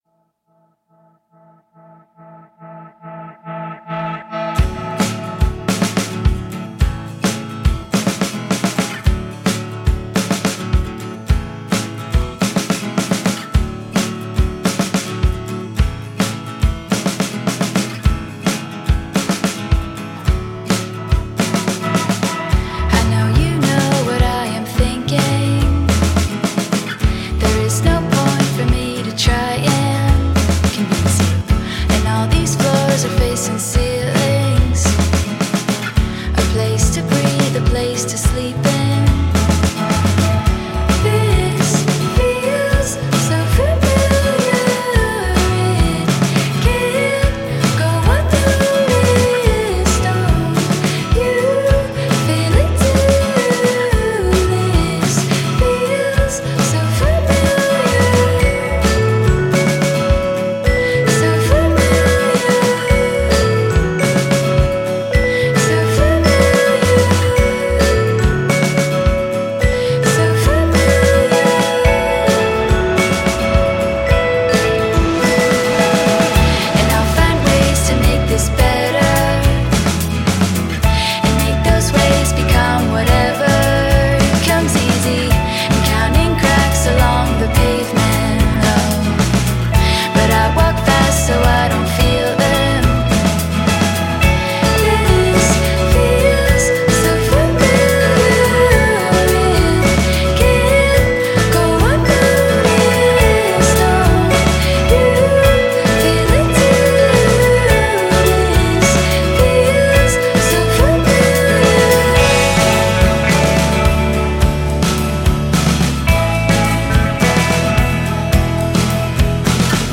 Immediately approachable and inviting
indie folk, pop, and rock